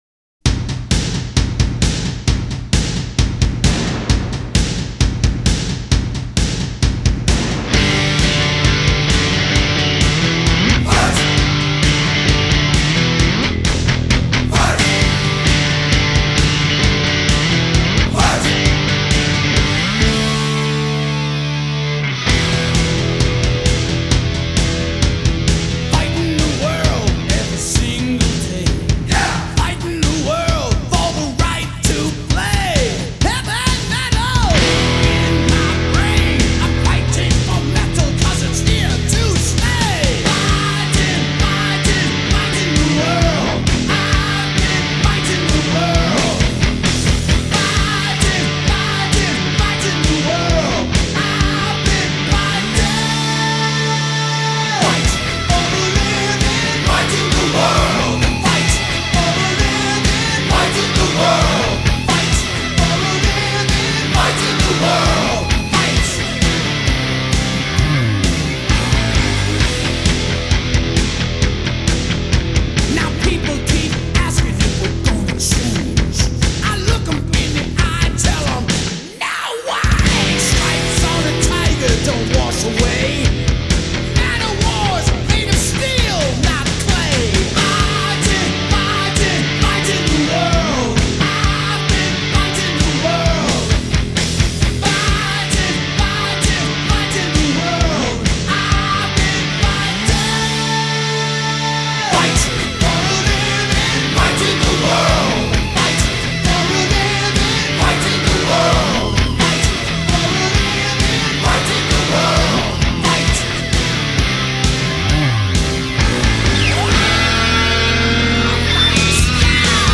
For me mostly hard rock, heavy metal and power metal.